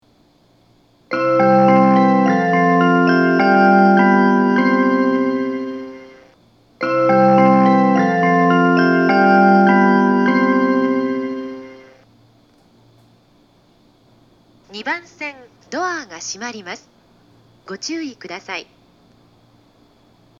発車メロディーは南武線オリジナルメロディーです。
発車メロディー
2コーラスです。こちらも終日鳴りやすいです。
ユニペックス小丸型（2・3番線）